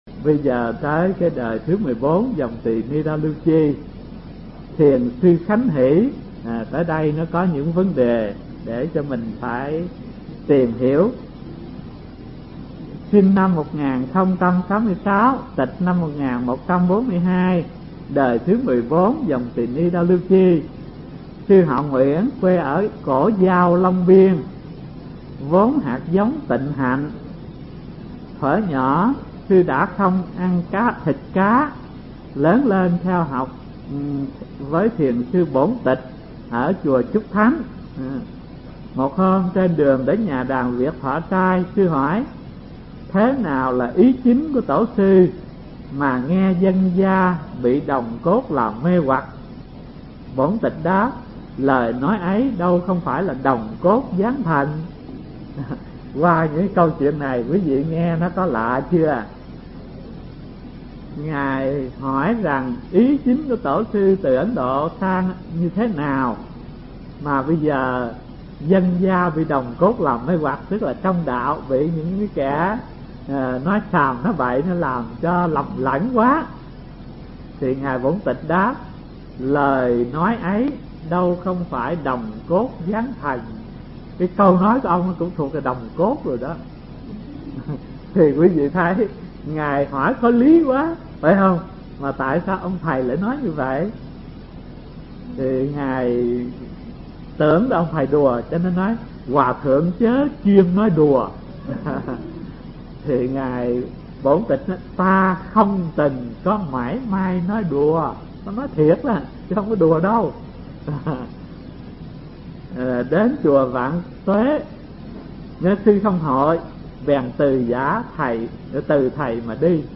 Mp3 Pháp Âm Thiền Học Phật Giáo Việt Nam 82 – Khánh Hỷ (Đời 14 Dòng Tỳ Ni Đa Lưu Chi) – Hòa Thượng Thích Thanh Từ giảng tại trường Cao Cấp Phật Học Vạn Hạnh, từ năm 1989 đến năm 1991